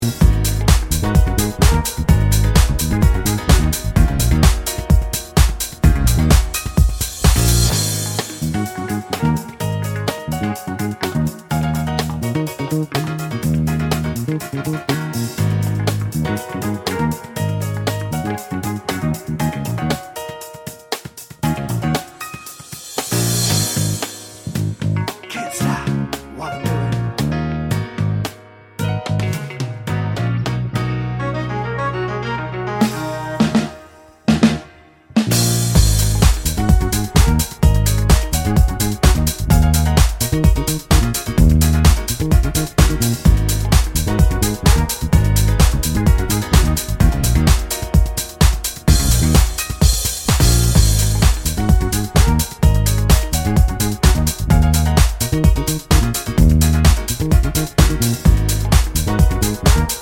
no Backing Vocals Dance 3:05 Buy £1.50